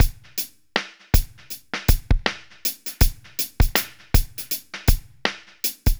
Index of /90_sSampleCDs/AKAI S6000 CD-ROM - Volume 4/Others-Loop/BPM_80_Others1